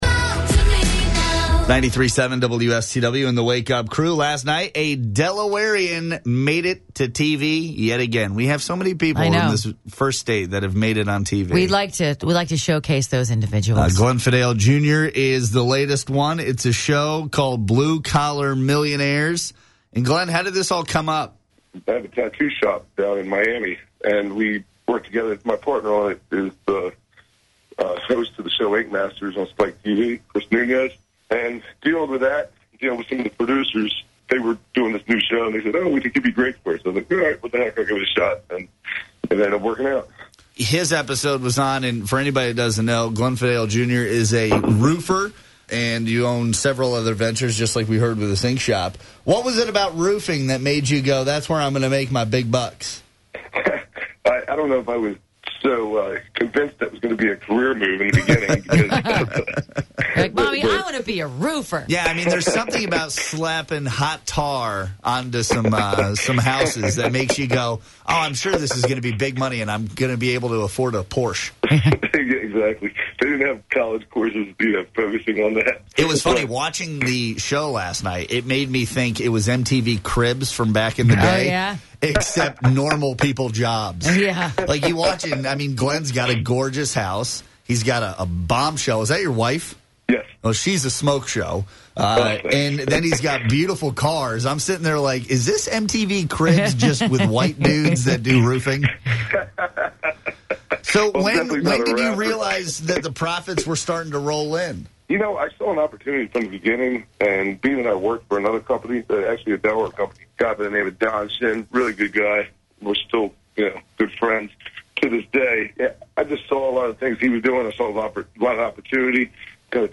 93.7 Radio Interview